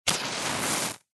Звук воспламенения сигнальной ракеты